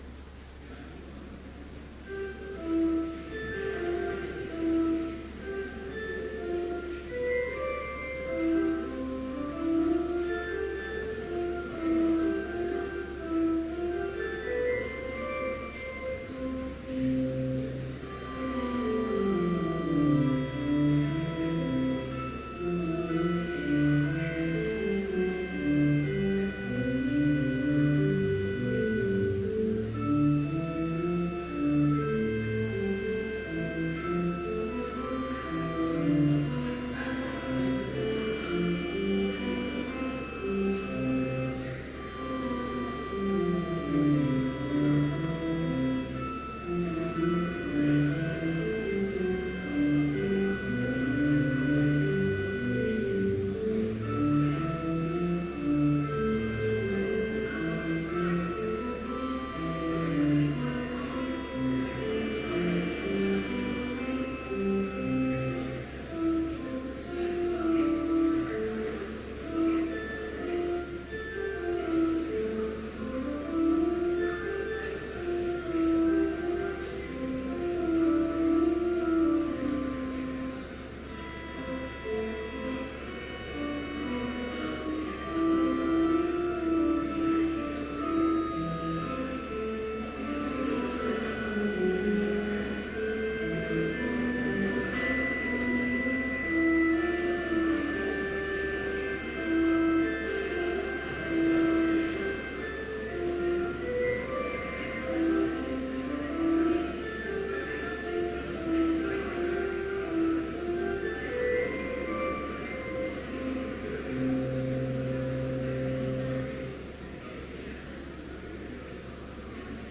Complete service audio for Chapel - April 23, 2015